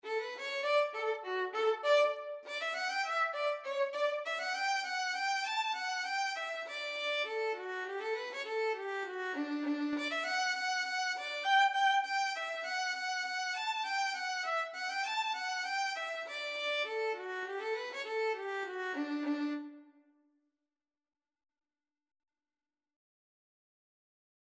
Violin version
D major (Sounding Pitch) (View more D major Music for Violin )
2/4 (View more 2/4 Music)
Violin  (View more Easy Violin Music)
Traditional (View more Traditional Violin Music)
Irish
ON413_leather_away_VLN.mp3